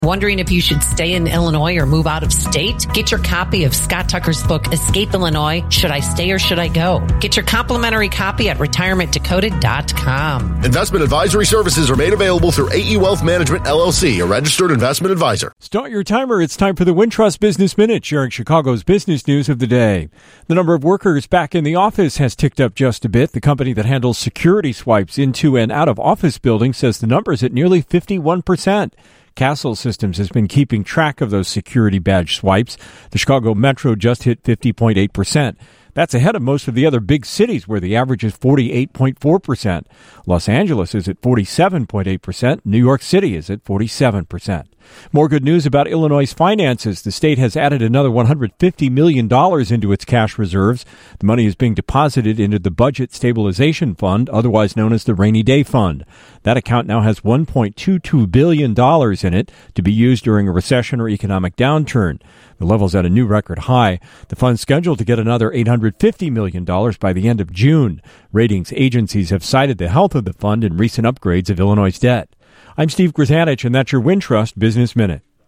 business news